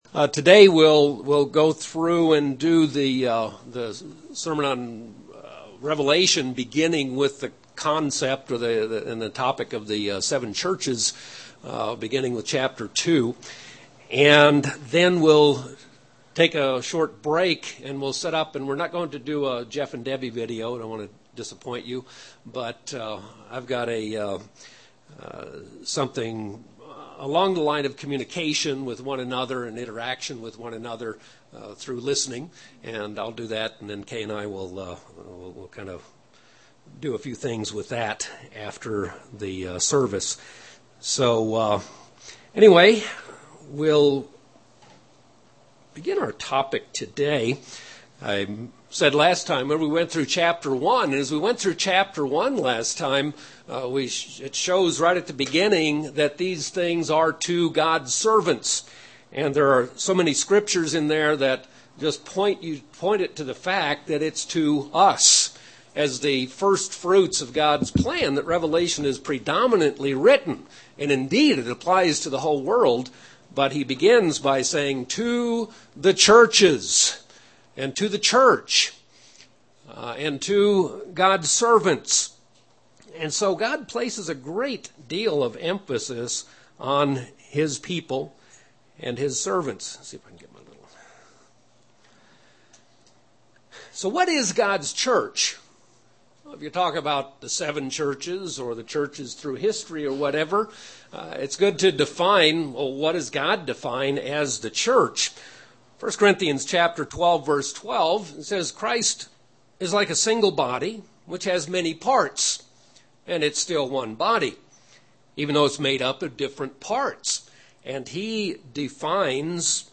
A continuation of a sermon on Revelation.